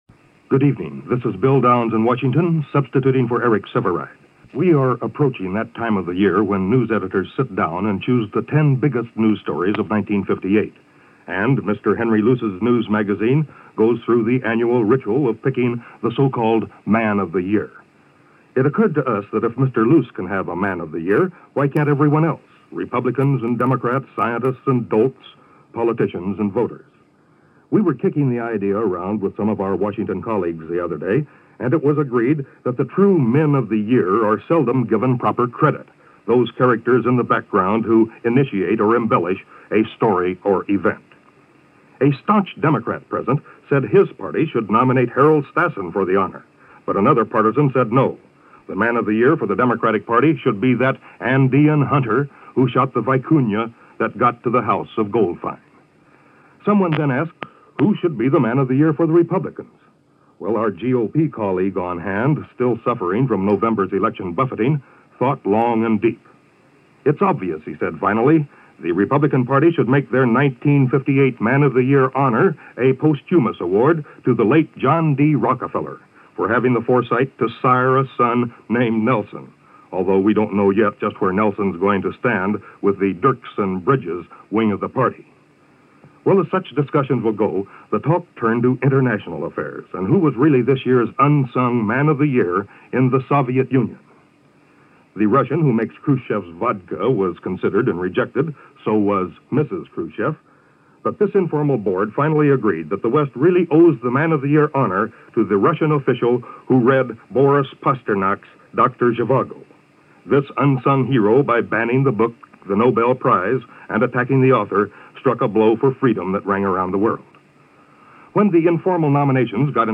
News reports, December 24-31, 1958 – CBS Radio News
And to remind you, here are several commentaries from CBS Radio News for the period of December 24-31, 1958.